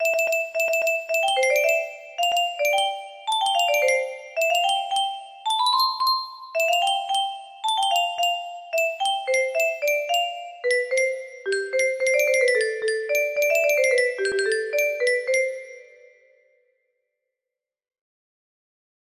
Christmas Medley music box melody